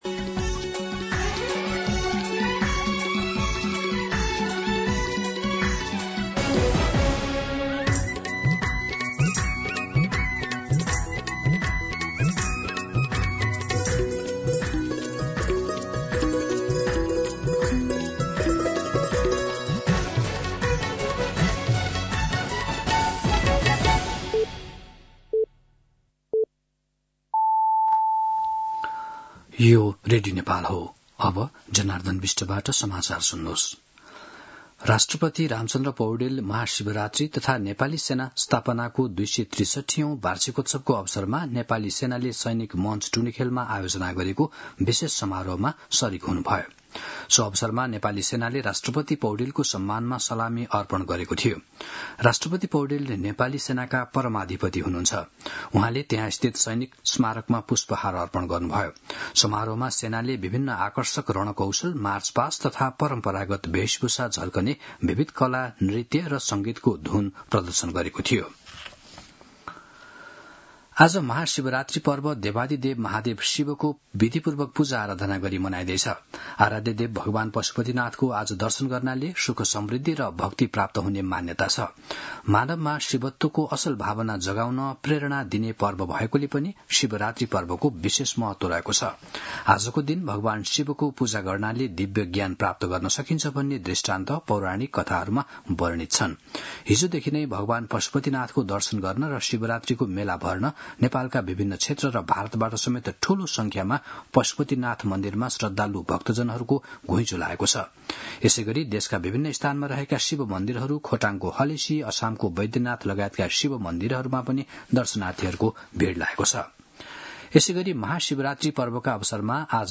दिउँसो १ बजेको नेपाली समाचार : ३ फागुन , २०८२